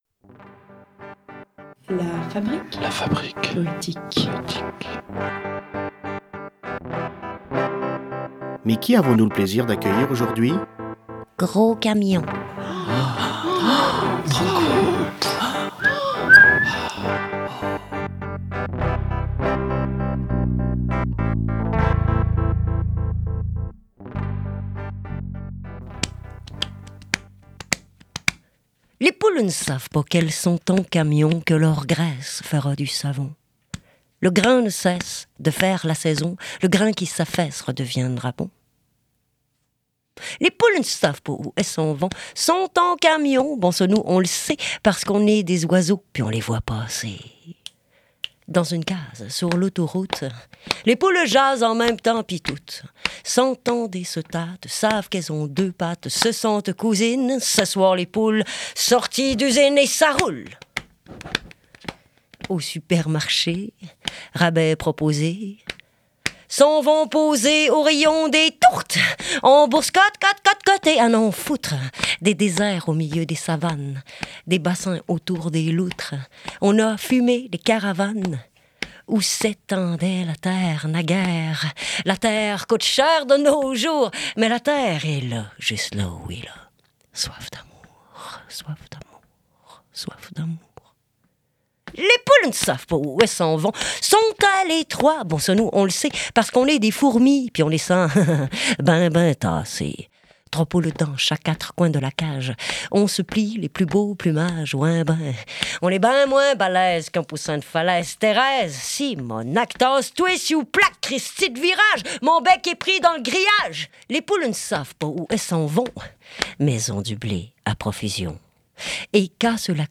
slam